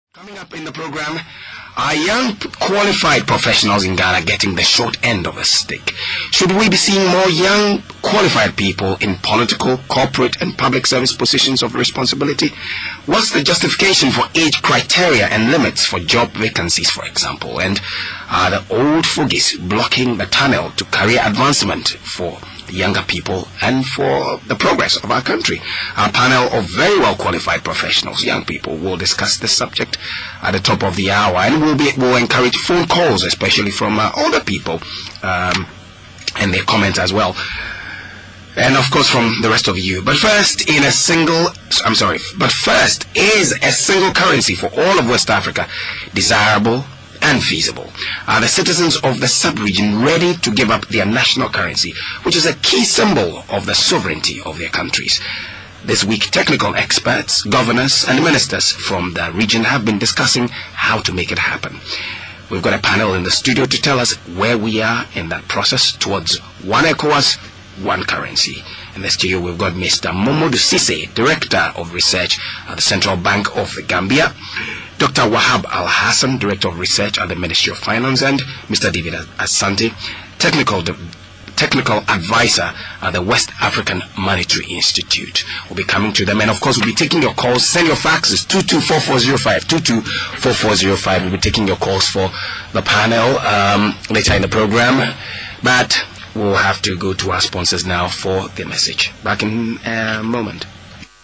English in Ghana
New English in Ghana is characterised by such features as the lack of syllable-final /r/, no vowel length distinctions, no diphthongisation of mid vowels, e.g. go [go], take [tek], and a general tendency to use syllable-timing (more or less equal length for all syllables in words, whether stressed or unstressed).
Ghana_Acrolectal.wav